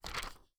pageturn1.wav